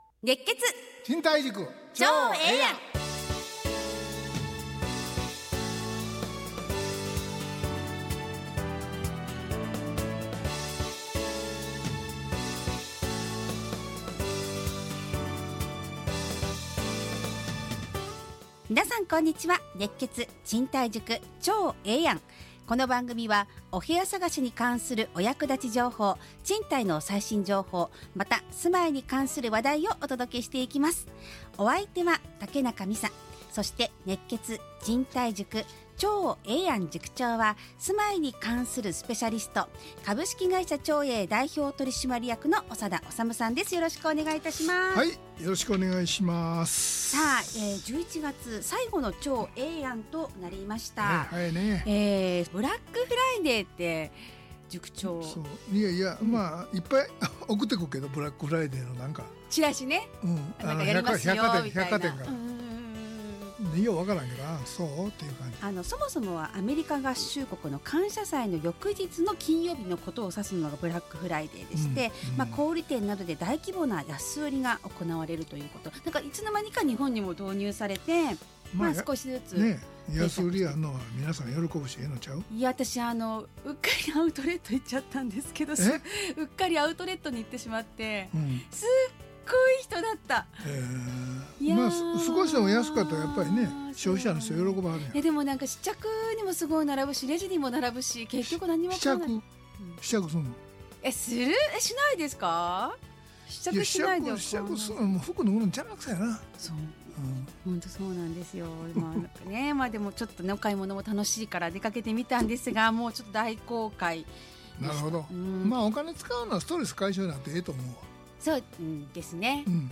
ラジオ放送 2025-12-01 熱血！